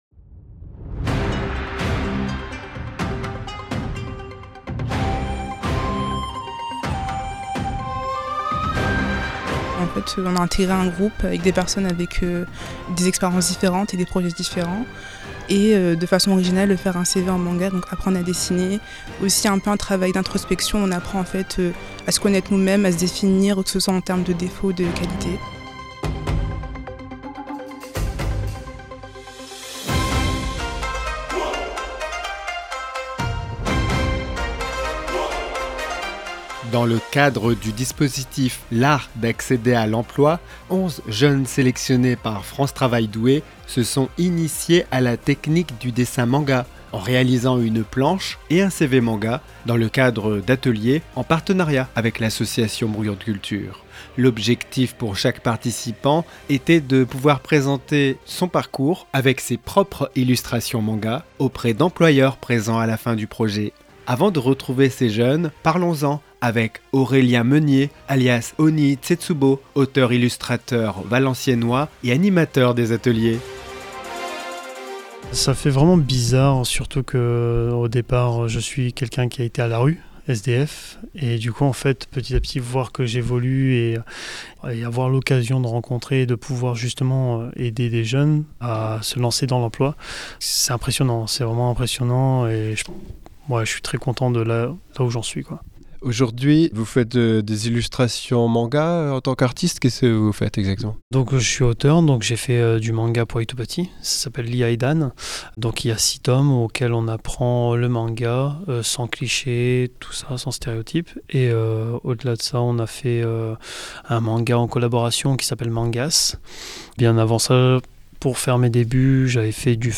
Les jeunes demandeurs d’emploi qui ont participé aux ateliers
REPORTAGE-2602-Des-jeunes-realisent-une-planche-et-un-CV-manga-a-Douai.mp3